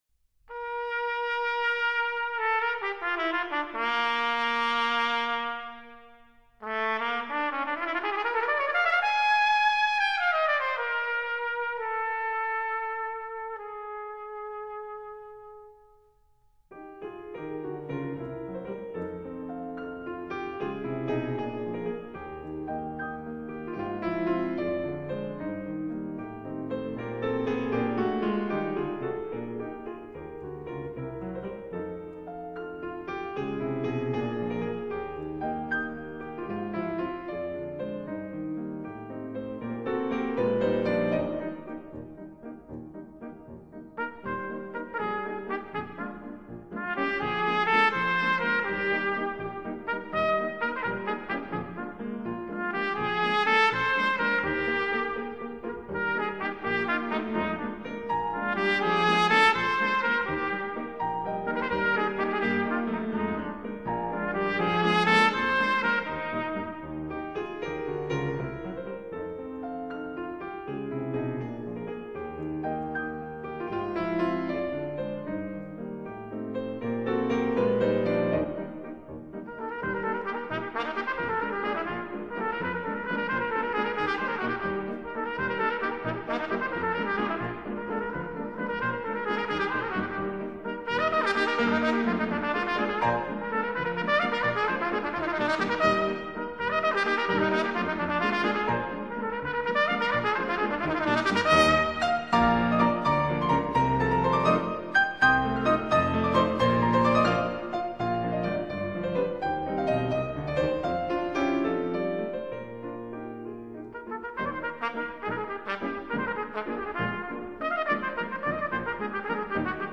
for cornet/trumpet & piano/band